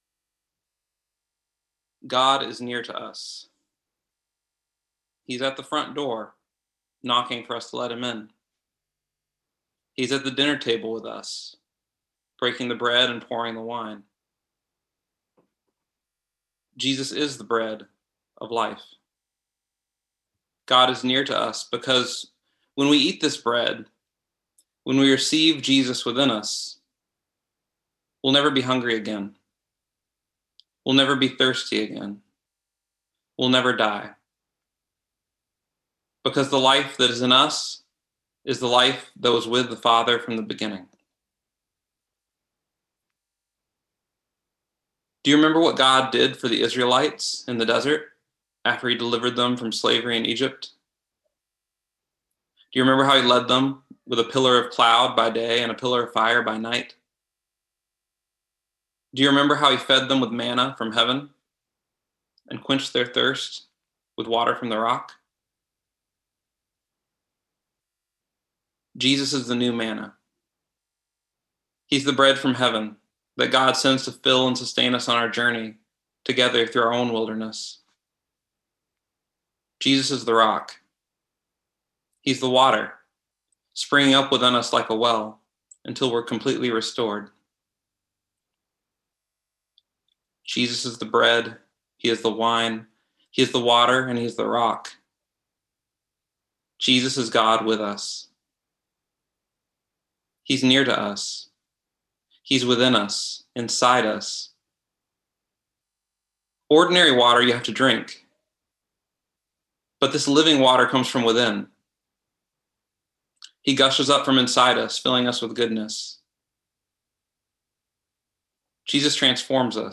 Listen to the most recent message from Sunday worship at Berkeley Friends Church, “I Am the Bread of Life.”